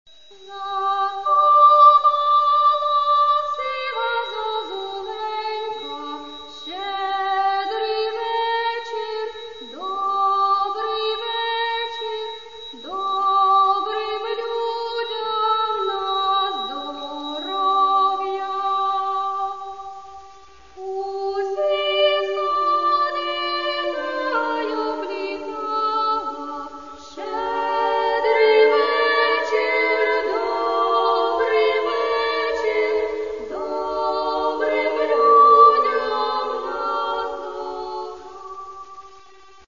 Співають діти